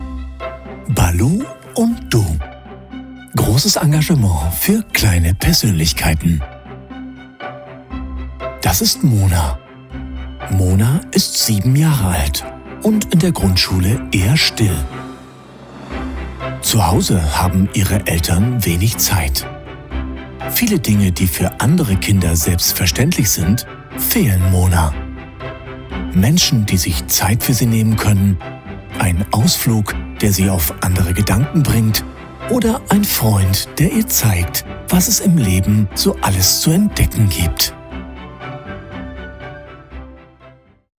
Kommerziell, Tief, Unverwechselbar, Zuverlässig, Warm
Unternehmensvideo